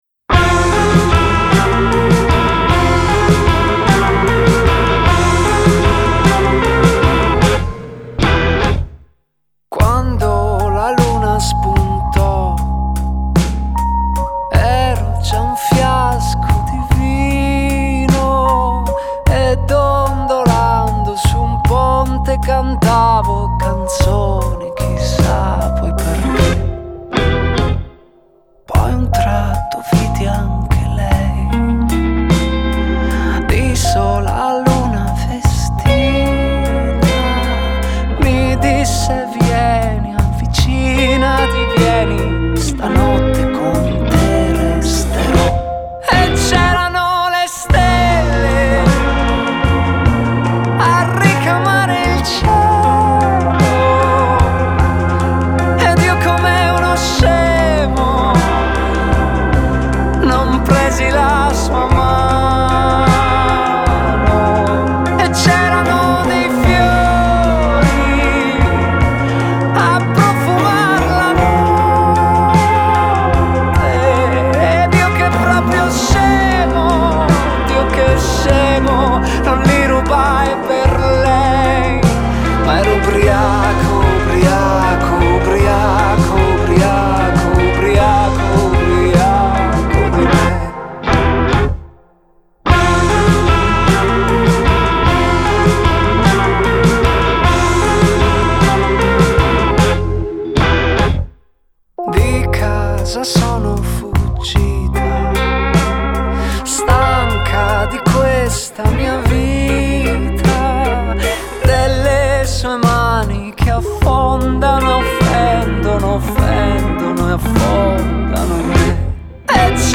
Genre: Pop Rock, Indie, Alternative